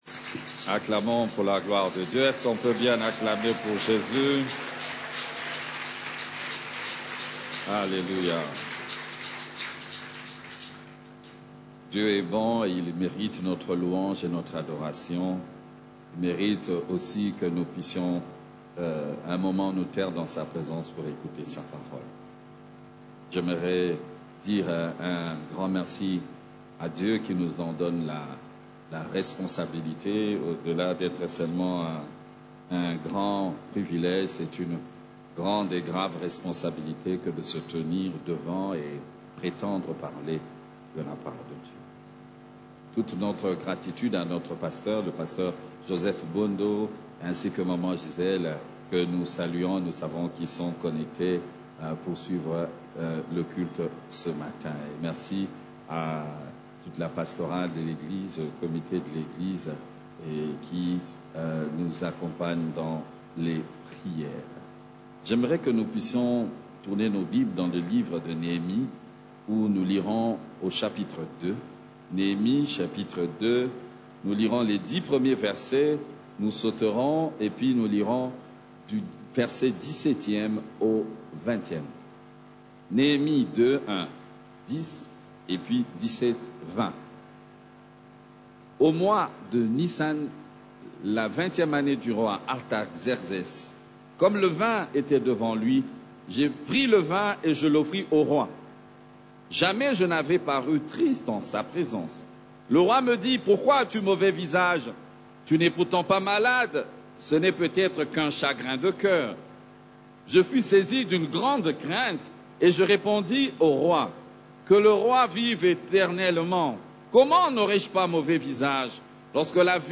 CEF la Borne, Culte du Dimanche, Levons-nous et bâtissons